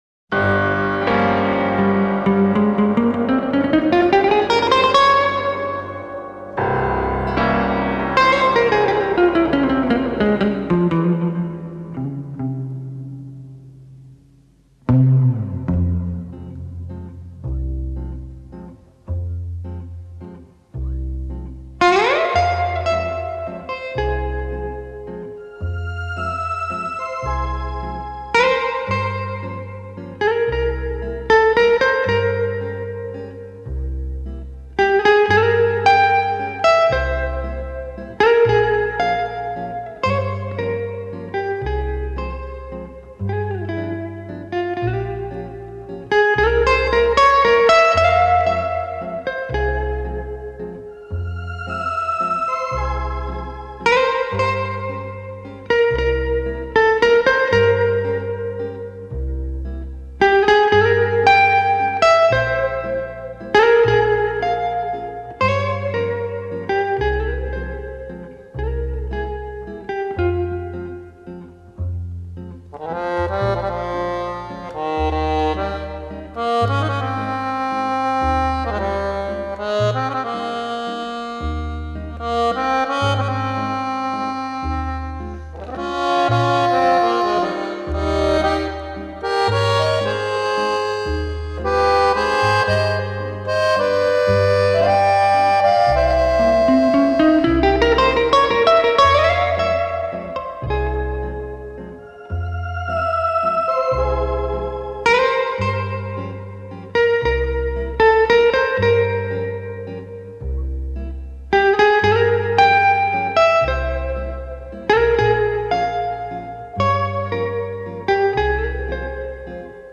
гитару